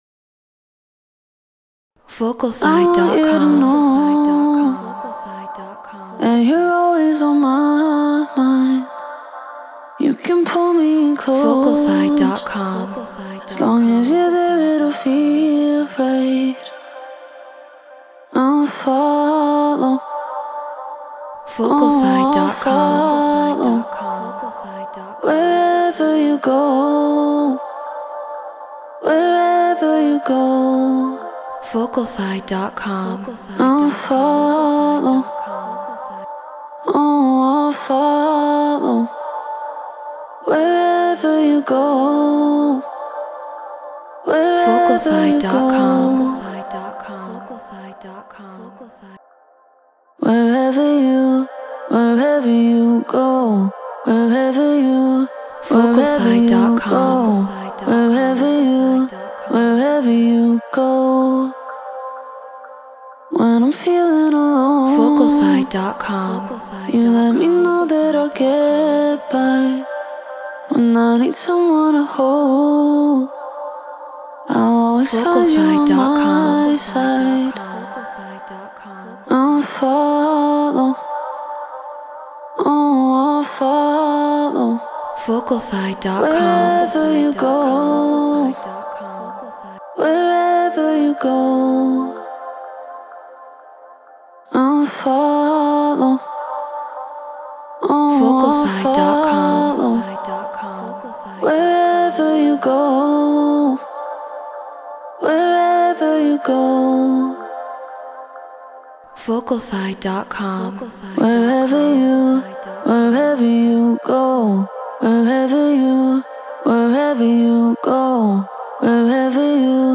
Deep House 126 BPM A#maj